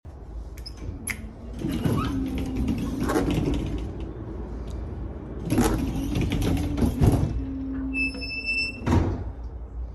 Tw6000 Tür Öffnung und Schließung